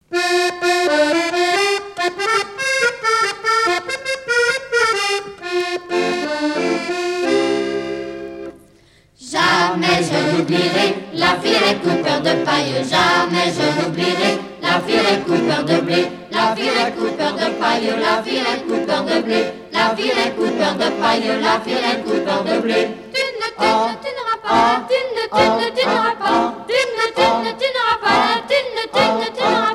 Couplets à danser
danse : branle : courante, maraîchine ;